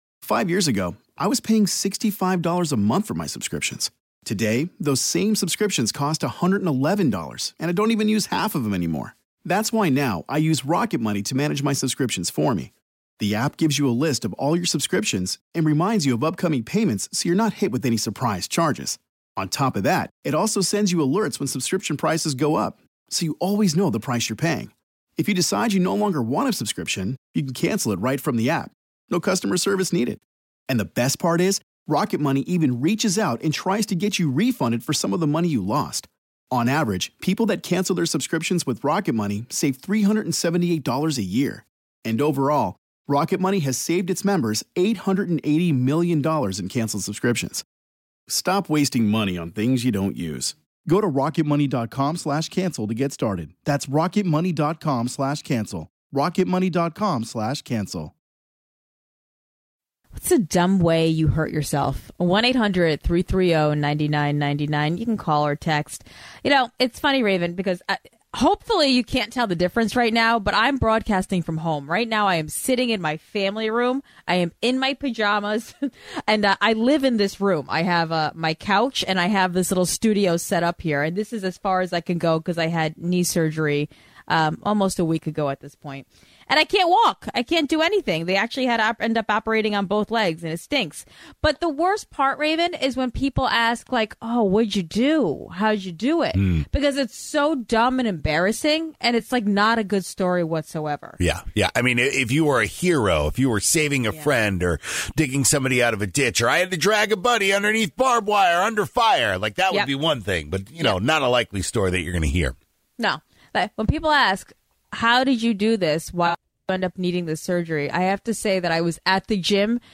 is broadcasting from home